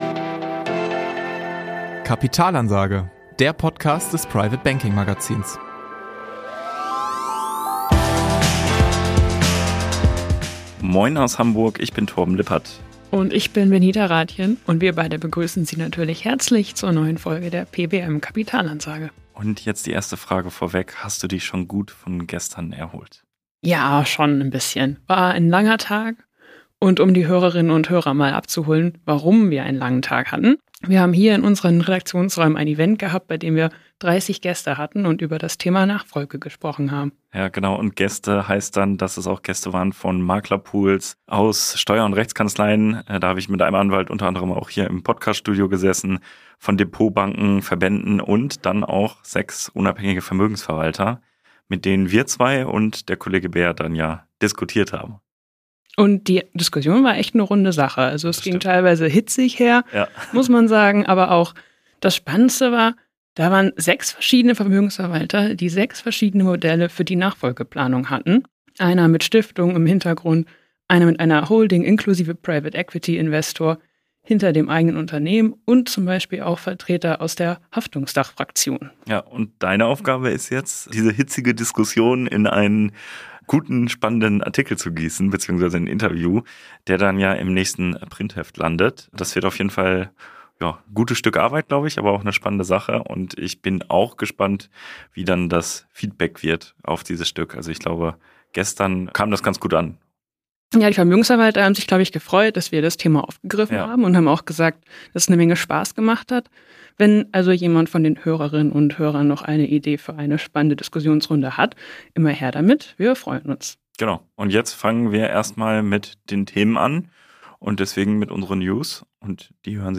ins Podcaststudio zurück und nehmen für die pbm kapitalansage die neuesten Entwicklungen der Branche unter die Lupe. Auch dabei spielt die Nachfolgeplanung eine Rolle.